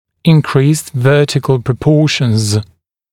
[ɪn’kriːst ‘vɜːtɪkl prə’pɔːʃnz][ин’кри:ст ‘вё:тикл прэ’по:шнз]увеличенные вертикальные пропорции